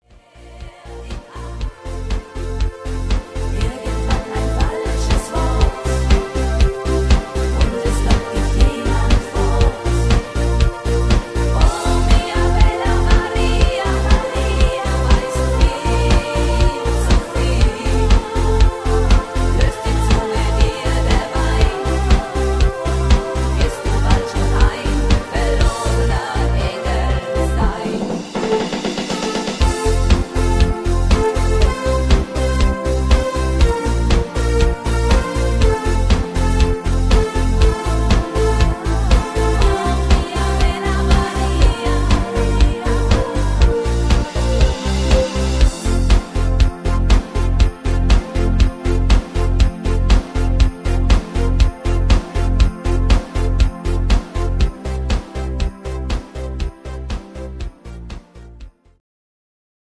DJ Version 2008